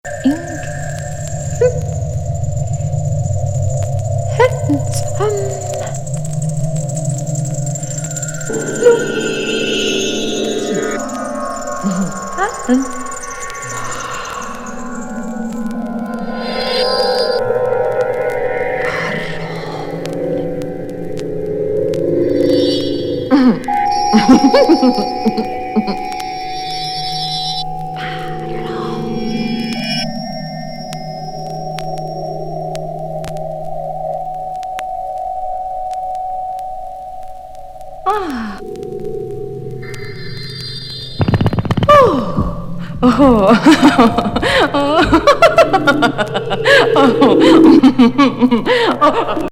電子音と関連して、女性の奇妙な泣き笑い声、様々なスピーチ(?)や叫びが
トルコ人作曲家によるアンビエント調な習作。